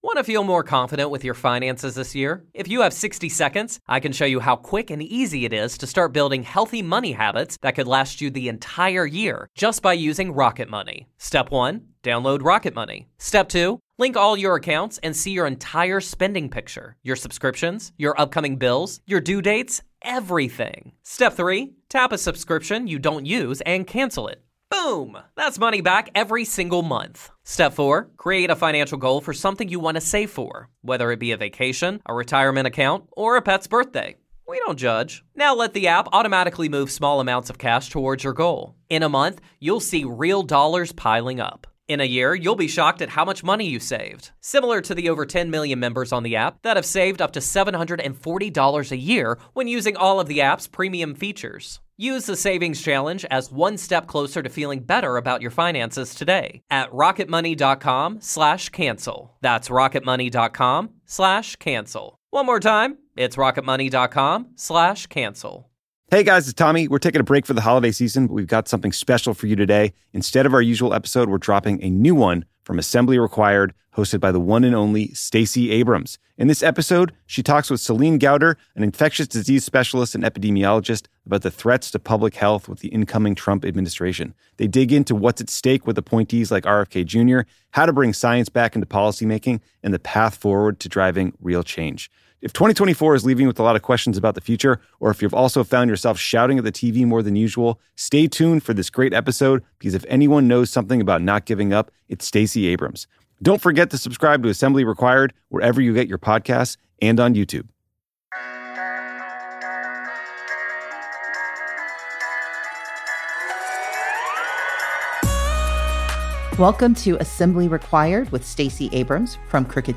In this episode, Stacey speaks to Dr. Céline Gounder, an infectious disease specialist, epidemiologist and editor at large for public health at KFF Health News, about the threats to public health with the incoming Trump Administration. They talk through Trump’s appointees for the nation’s top health and science agencies like RFK Jr., how to bring scientific thinking back to policy making, and how to differentiate between public health and individual healthcare.